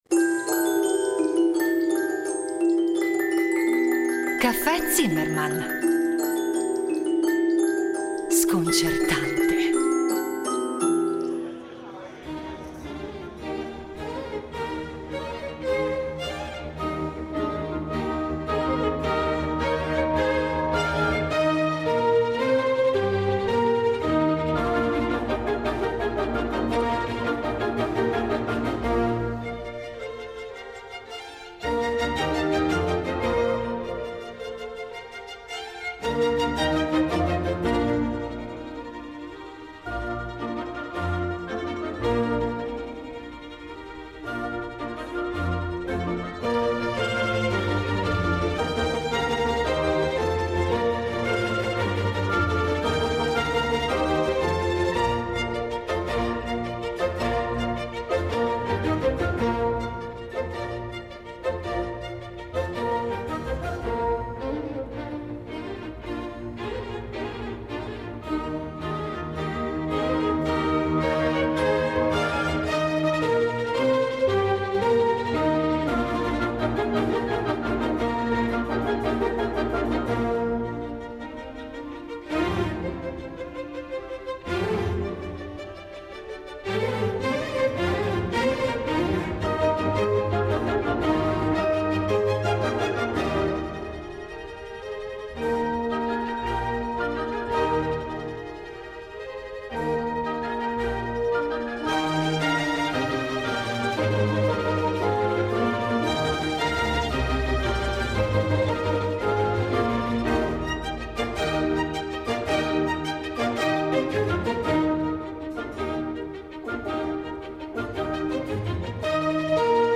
il musicologo